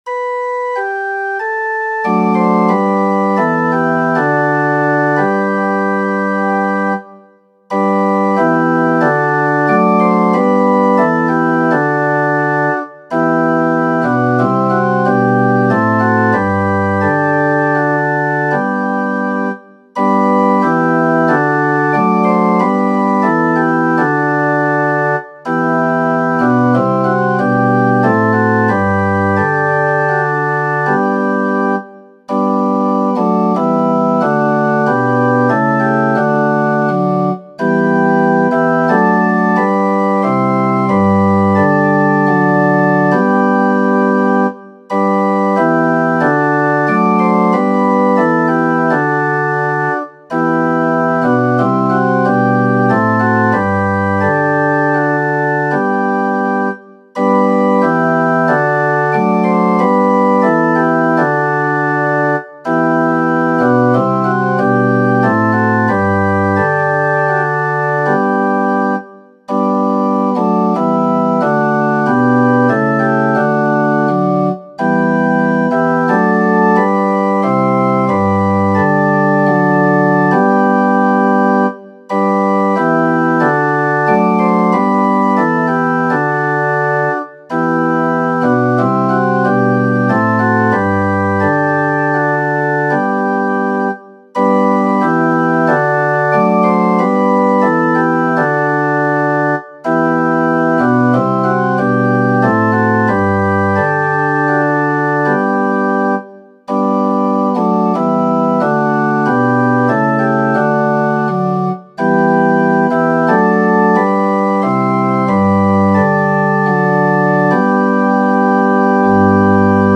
Culte du 8 novembre 2020
Lecture 1 thess, 5, 1-5.mp3 (3.03 Mo)